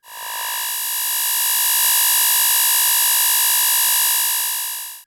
Hum21.wav